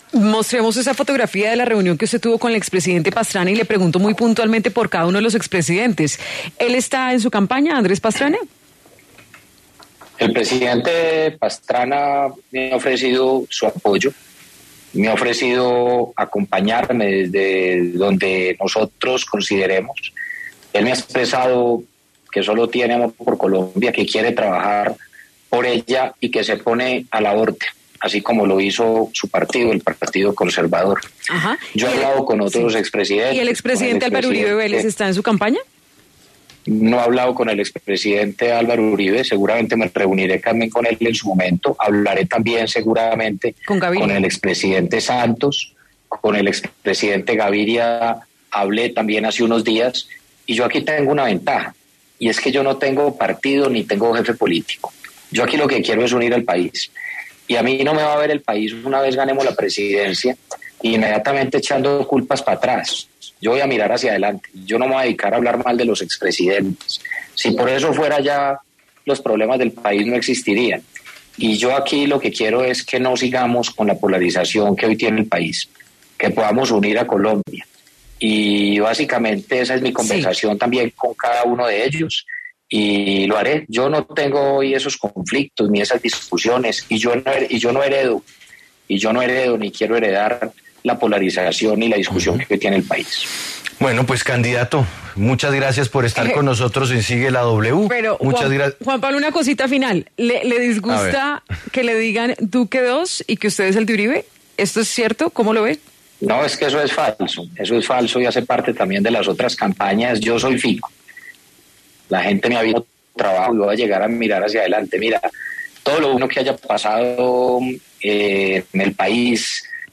Siguiendo con la entrevista, ‘Fico’ dio detalles de su encuentro con el expresidente Andrés Pastrana, en el que hablaron sobre los retos de su campaña.